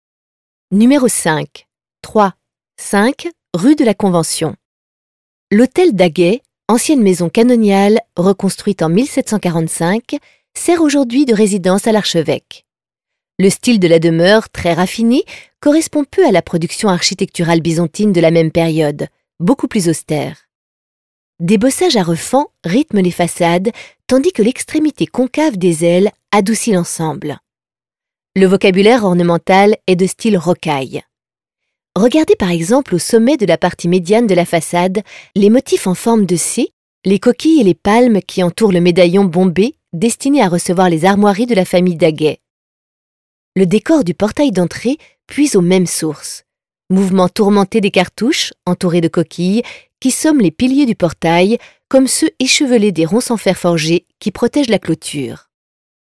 Ecouter l'audio guide